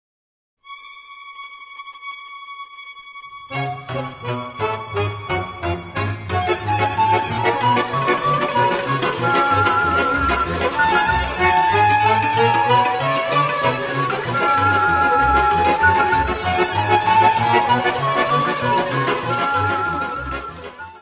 Área:  Tradições Nacionais